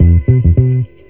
FINGERBSS7-L.wav